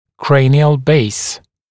[‘kreɪnɪəl beɪs][‘крэйниэл бэйс]основание черепа